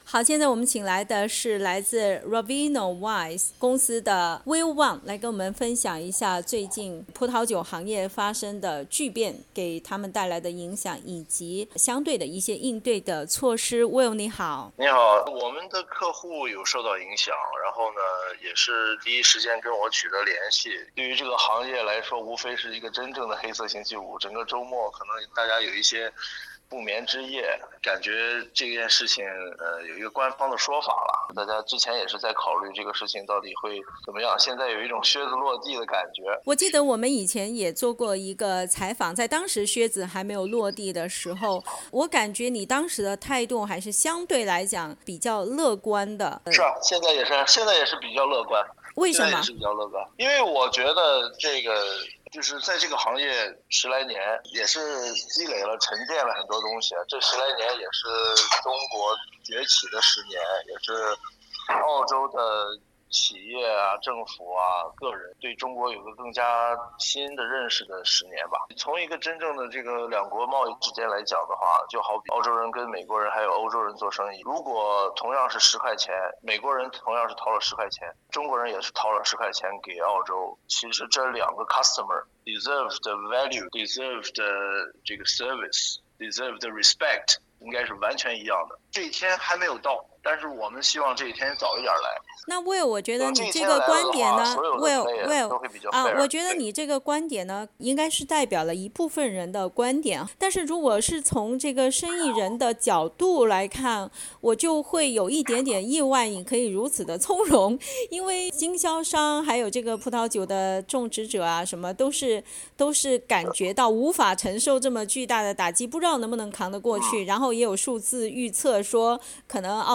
请关注我们的系列专访：靴子落下后的澳洲红酒华人企业何去何从？